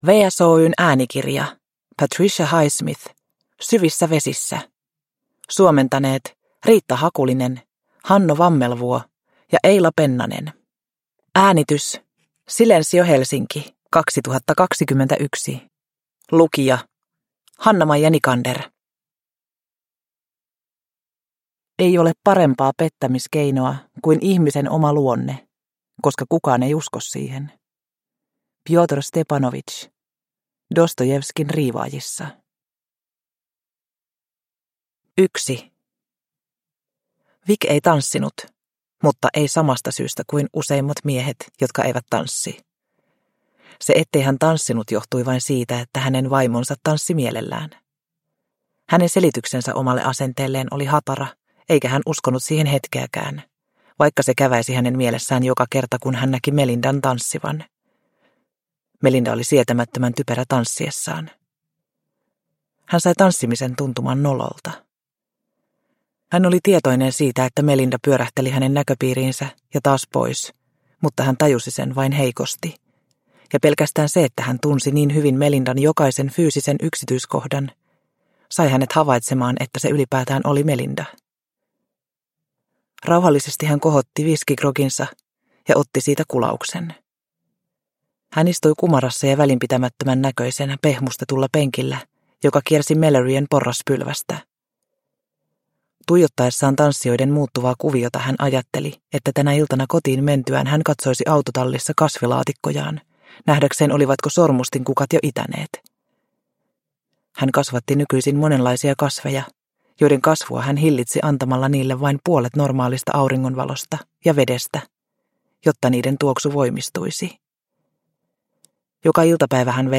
Syvissä vesissä – Ljudbok – Laddas ner